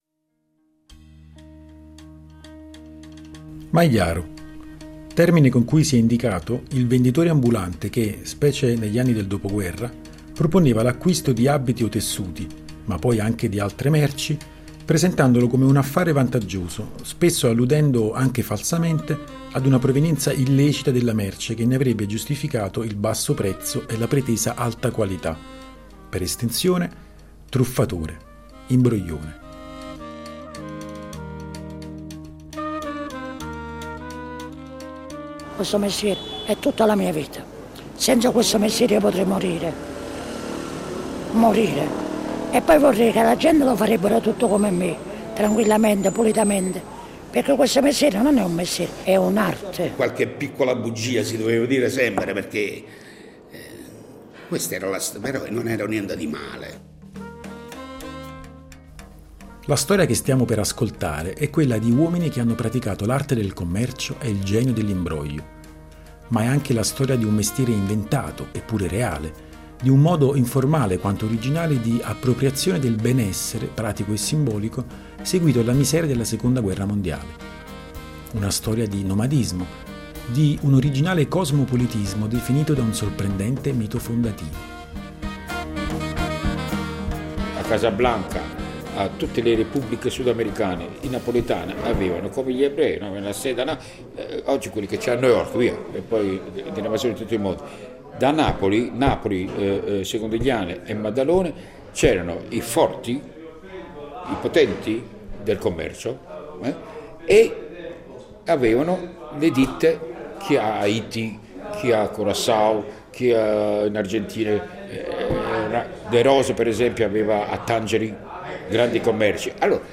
Questo documentario radiofonico